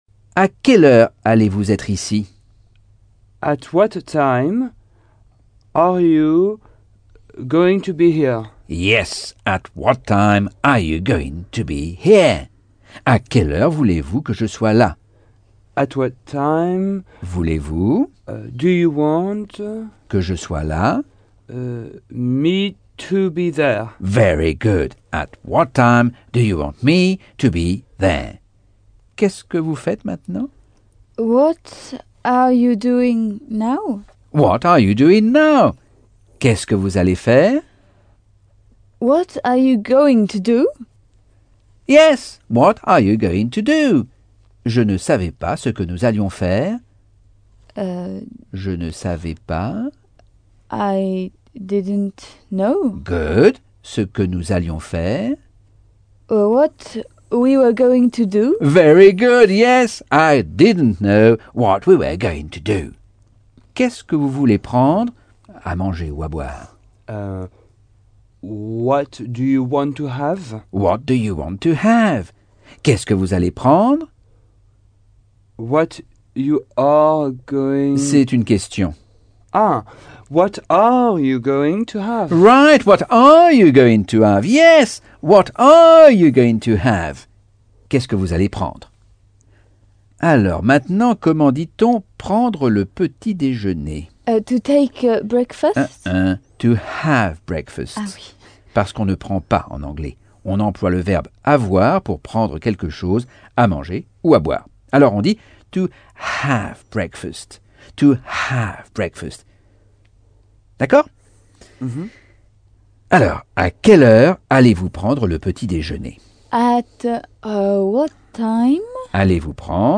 Leçon 5 - Cours audio Anglais par Michel Thomas - Chapitre 8